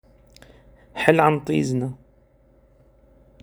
حل عن طيزنا Hel an tezzna عِبَارة سورية عبارة تقال لشخص ثقيل الظل من أجل يدعنا و شأننا، أو يرحل عن ناظرنا.